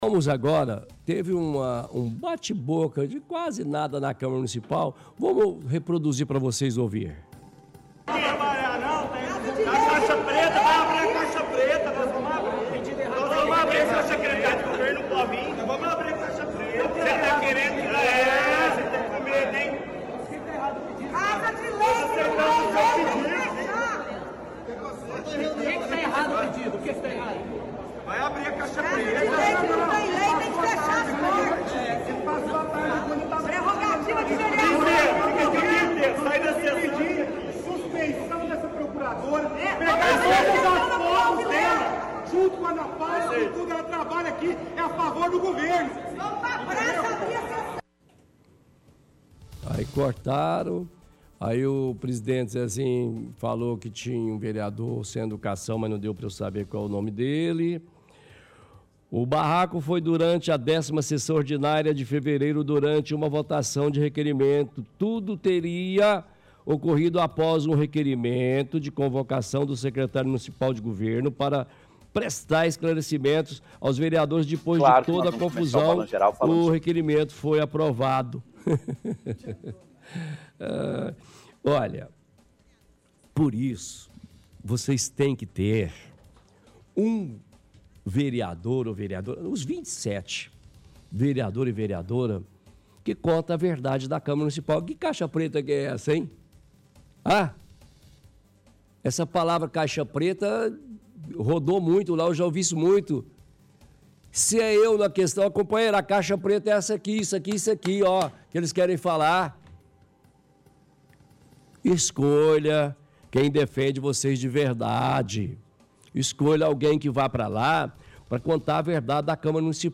– Transmissão de trecho de áudio de discussão entre vereadores em dia que estava sendo avaliada a convocação do secretário Marco Túlio.